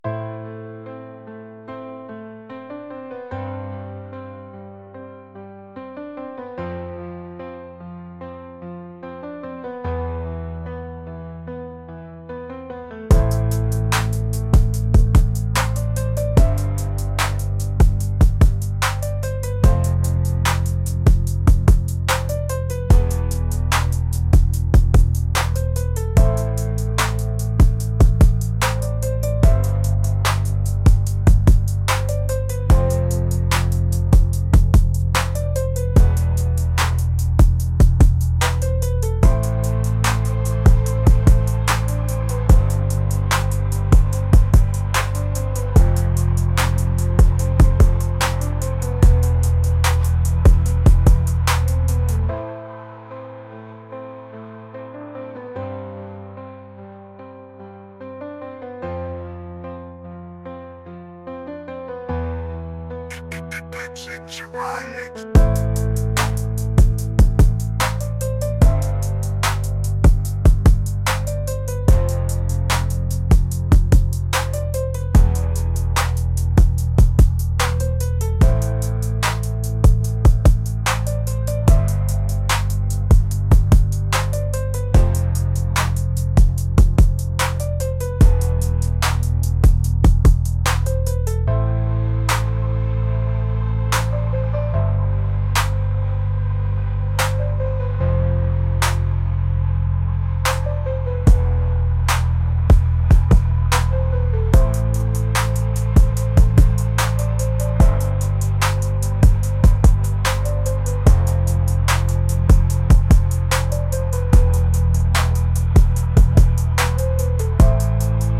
cinematic | pop | classical